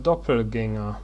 ^ أ ب From German Doppelgänger, تـُنطق [ˈdɔpl̩ˌɡɛŋɐ]